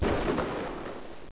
knall1b.mp3